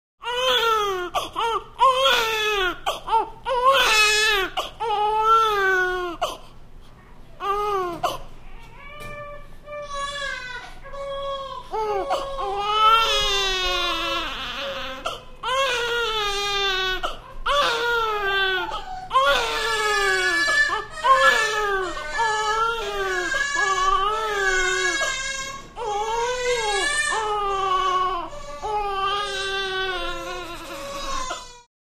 два младенца плачут в комнате